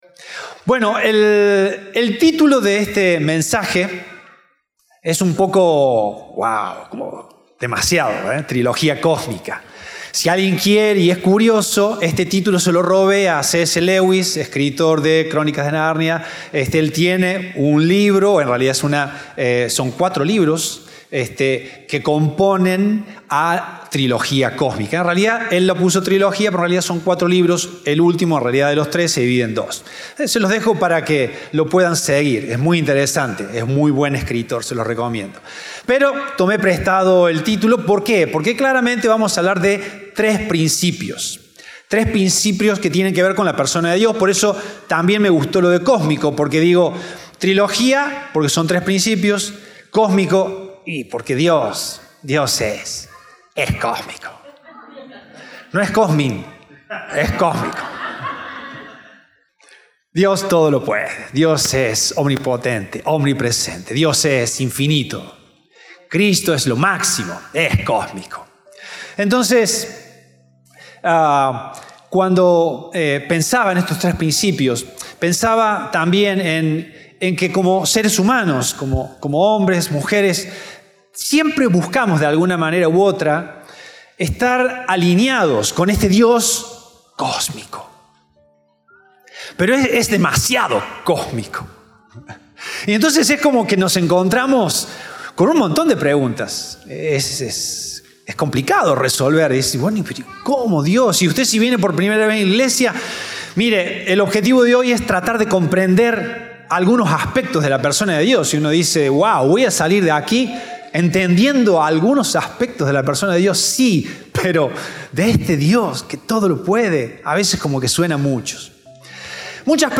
Compartimos el mensaje del Domingo 7 de Abril de 2024